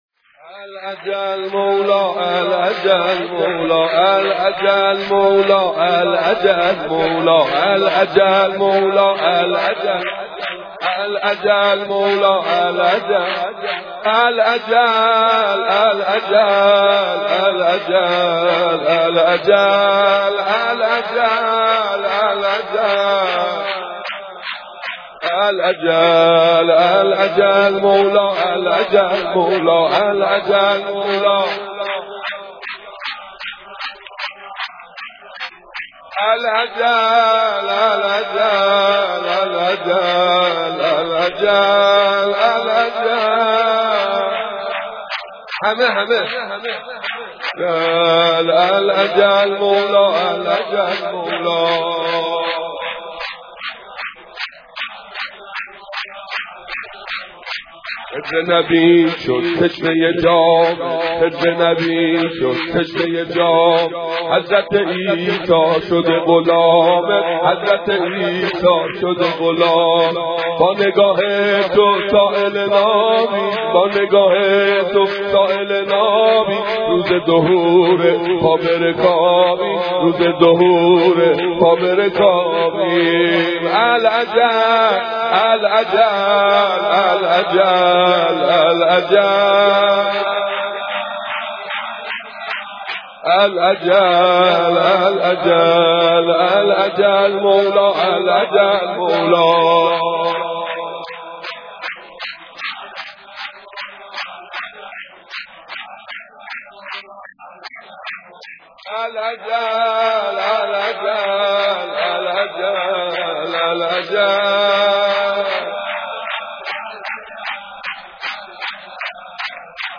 العجل مولا العجل مولا، العجل العجل العجل العجل (شور) - میلاد امام زمان (عجل الله فرجه)